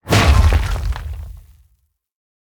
Minecraft Version Minecraft Version latest Latest Release | Latest Snapshot latest / assets / minecraft / sounds / item / mace / smash_ground3.ogg Compare With Compare With Latest Release | Latest Snapshot
smash_ground3.ogg